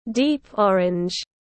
Màu cam đậm tiếng anh gọi là deep orange, phiên âm tiếng anh đọc là /diːp ˈɒr.ɪndʒ/.
Deep orange /diːp ˈɒr.ɪndʒ/